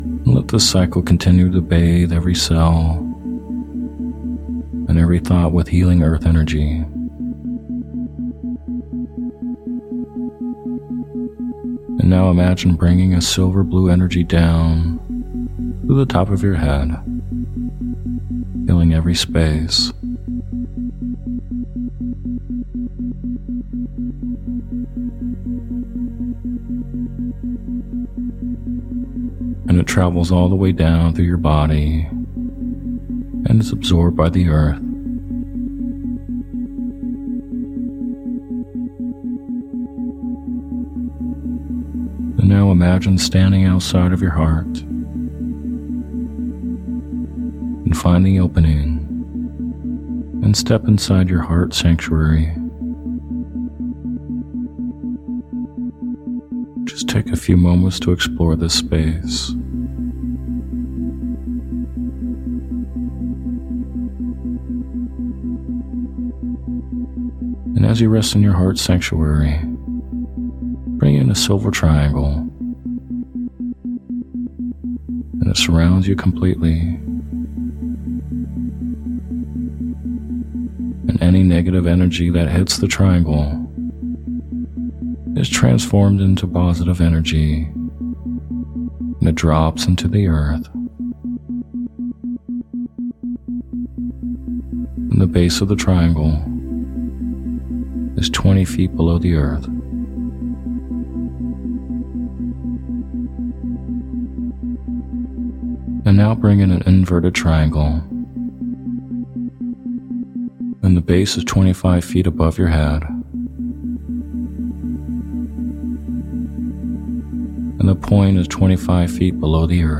Sleep Hypnosis For Chakra Protection With Isochronic Tones